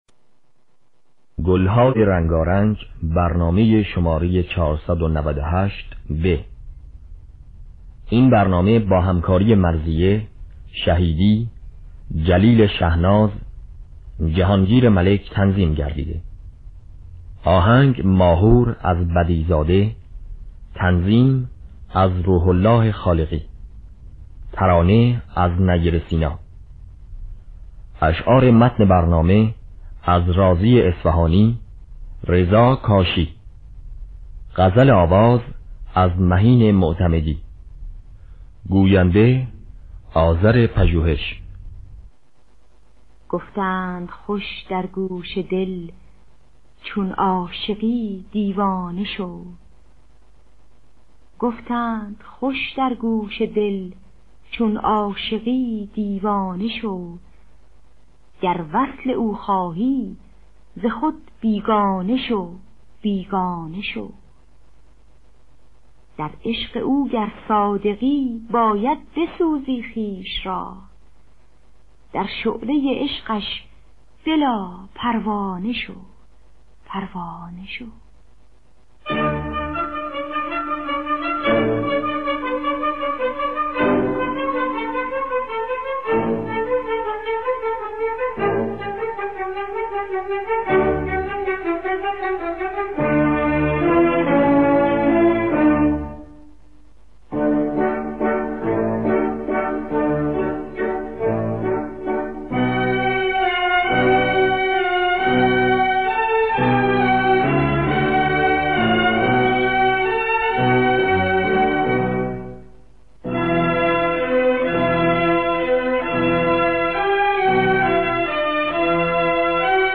گلهای رنگارنگ ۴۹۸ب - ماهور
خوانندگان: مرضیه عبدالوهاب شهیدی نوازندگان: جلیل شهناز جهانگیر ملک